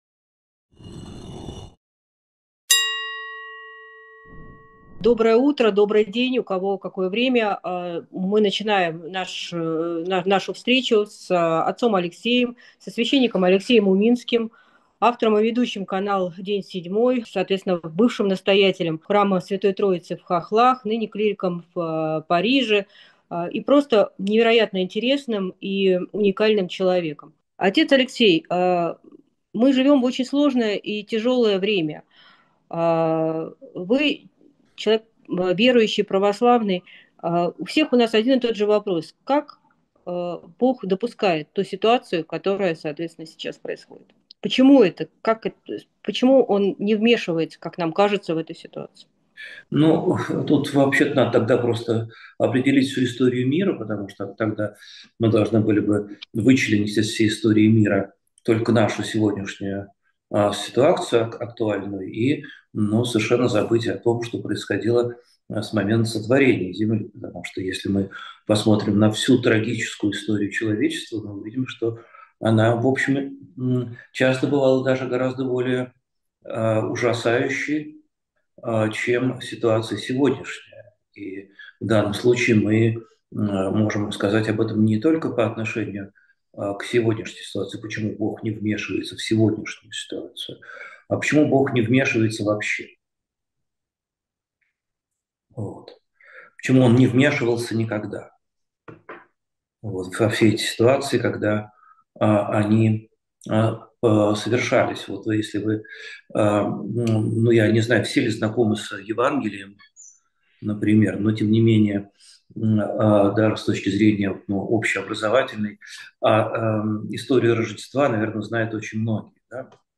Эфир ведёт Алексей Уминский
Алексей Уминский, интервью Ковчегу, 12.06.25